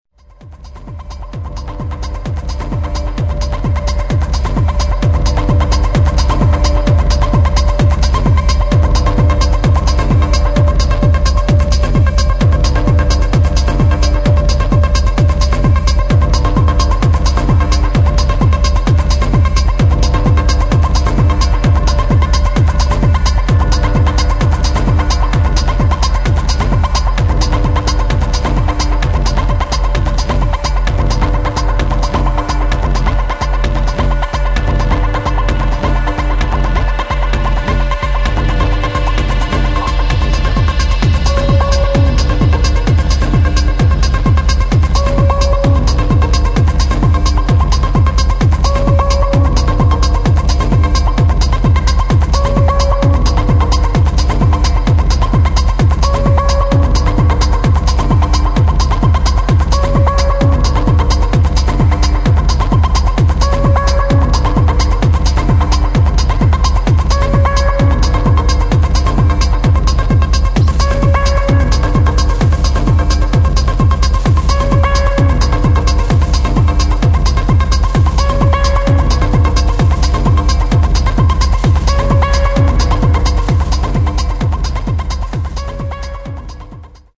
[ HOUSE | TECHNO | MINIMAL ]